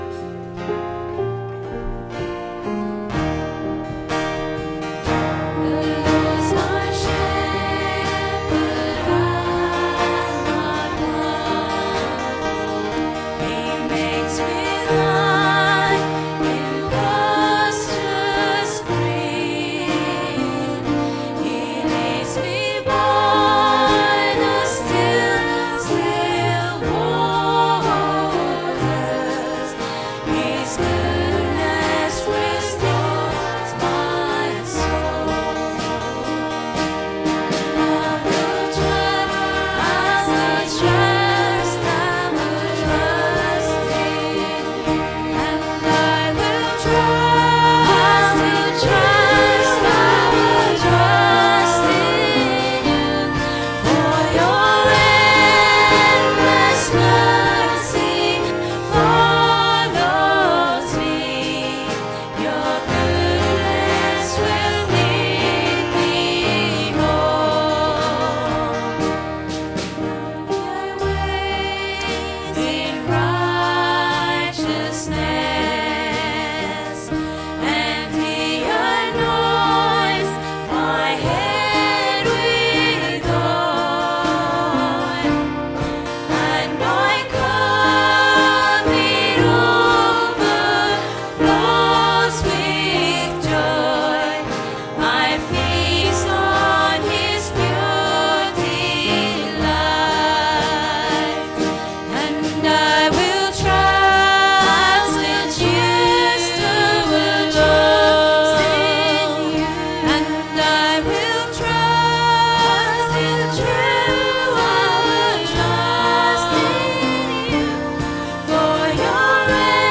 The next in our sermon series entitled 'We've got work to do!'
Service Audio